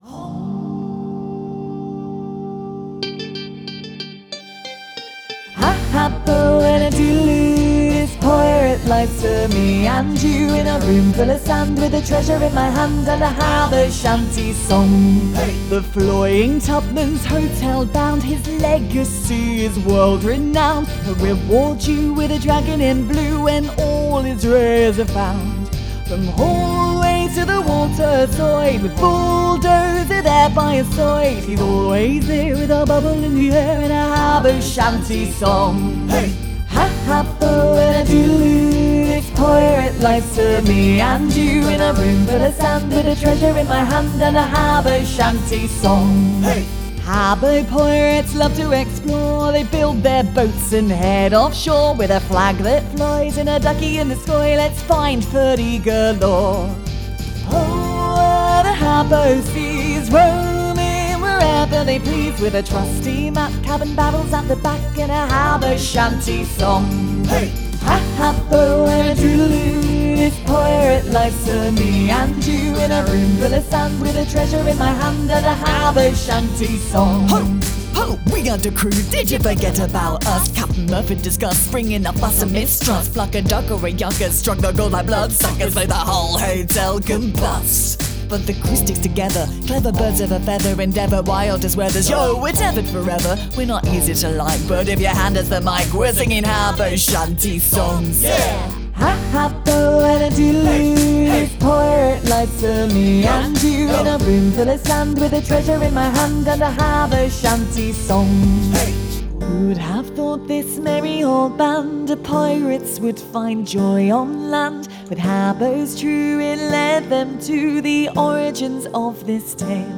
I wanted to make everything myself without AI, so created original music based on the console noise, sung, used questionable accents and added rap for absolutely no reason.
HABBO-SHANTY.mp3